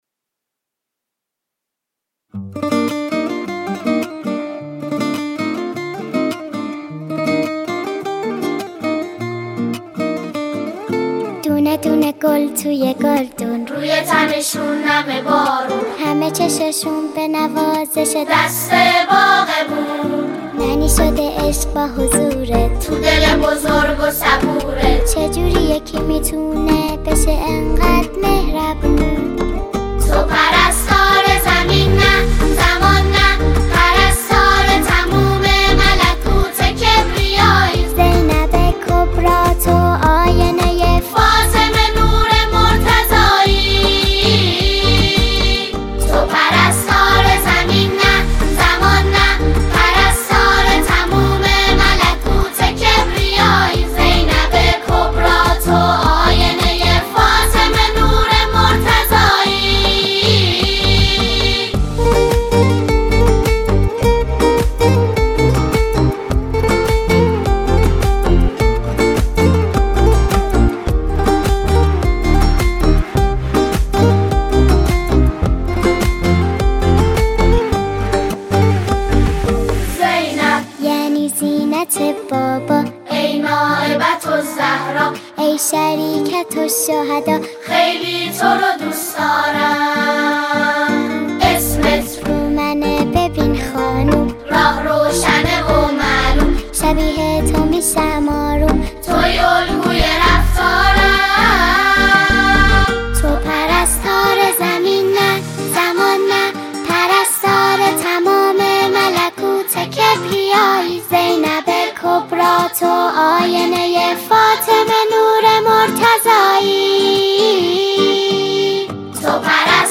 ویژه ولادت حضرت زینب سلام‌ الله‌ علیها و روز پرستار